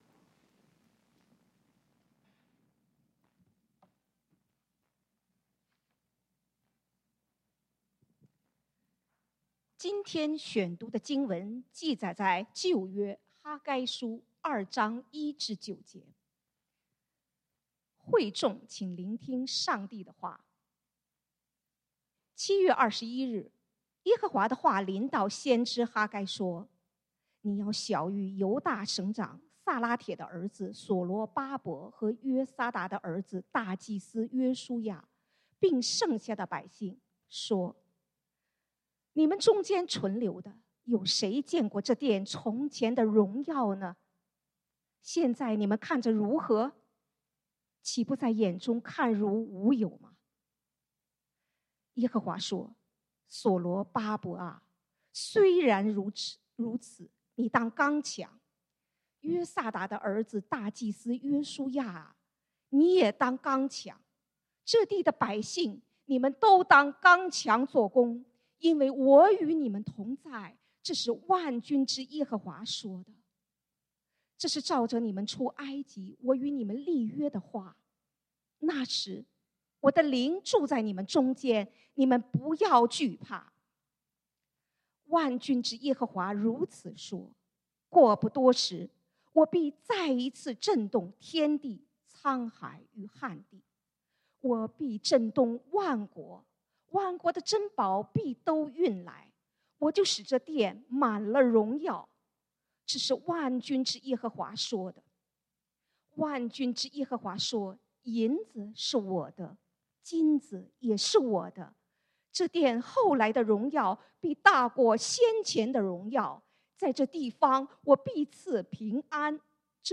11/6/2022 講道經文：哈該書 Haggai 2:1-9 本週箴言：彌迦書 Micah 6:8 世人哪，耶和華已指示你何為善。